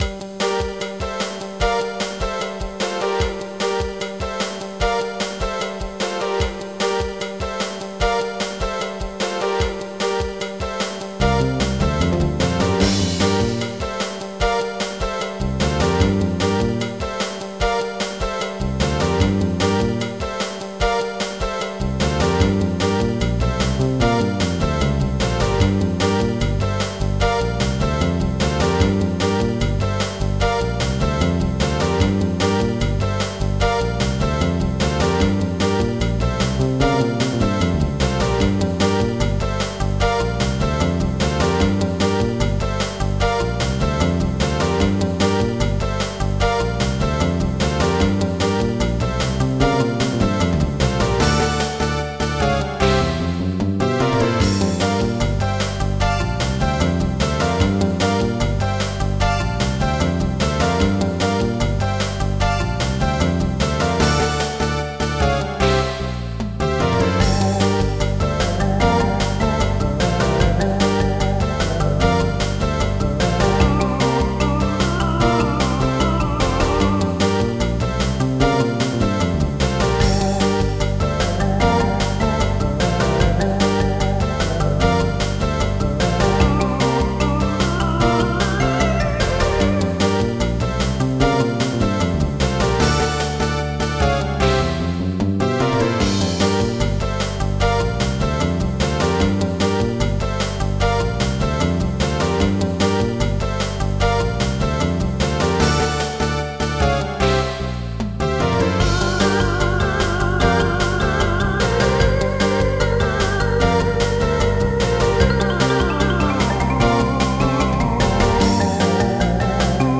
Title theme AWE (28.8k)